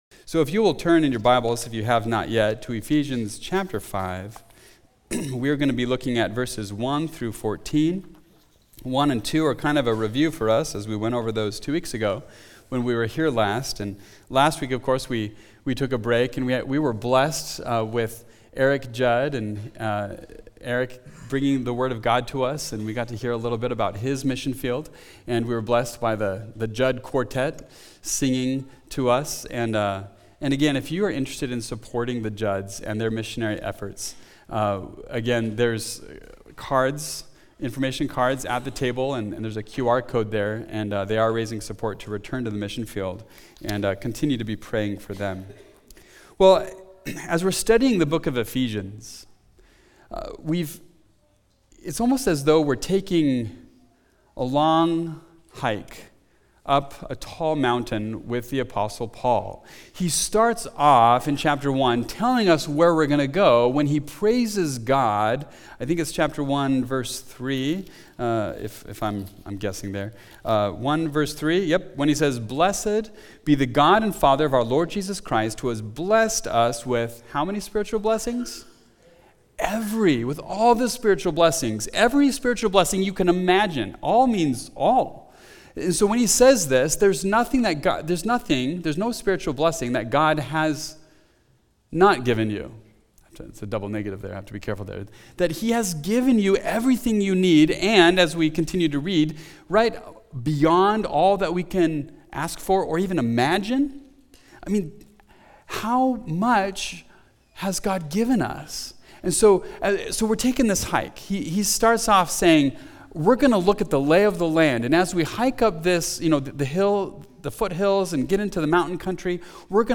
The Satisfying Sacrifice – Mountain View Baptist Church